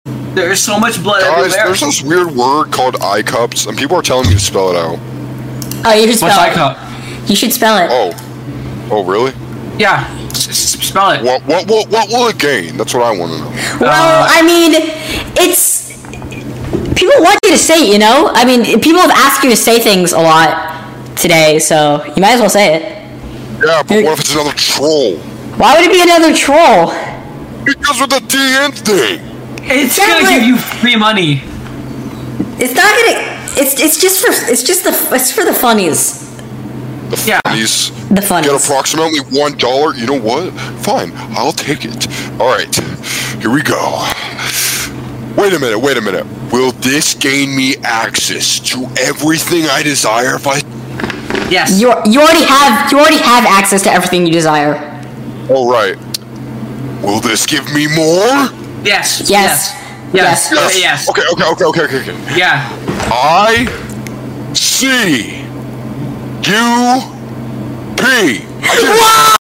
ITS VOICE IS SO DEEP